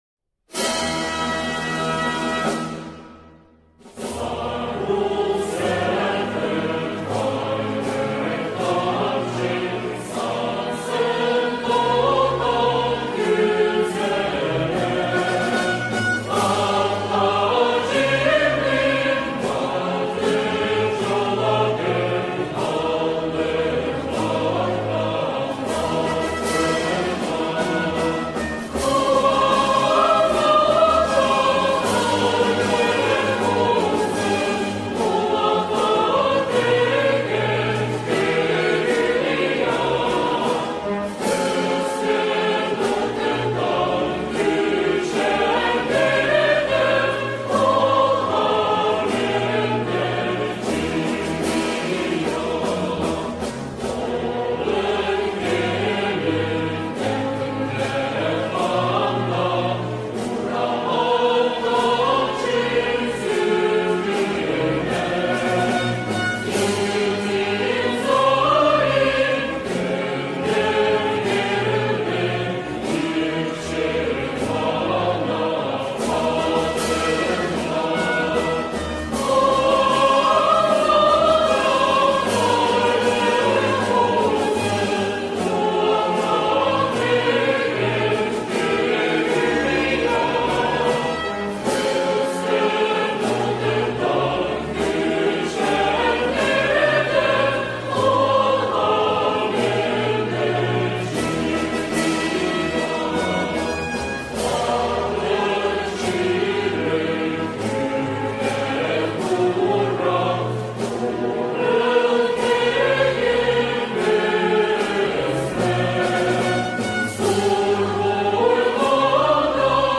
со словами